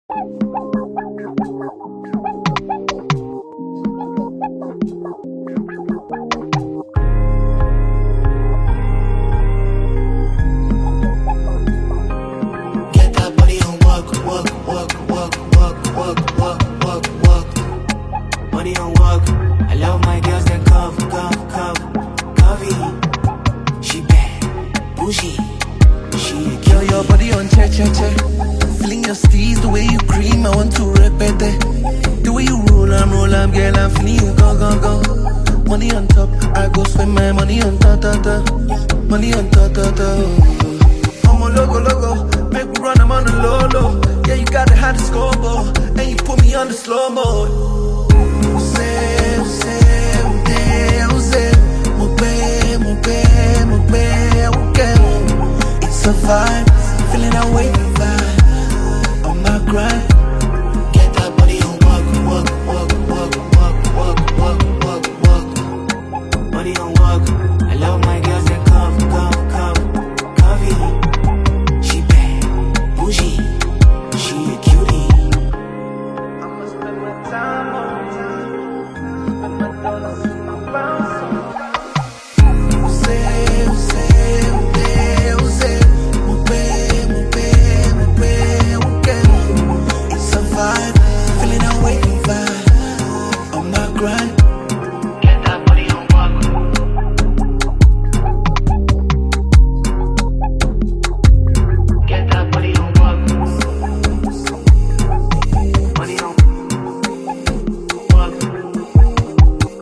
Award-winning Nigerian singer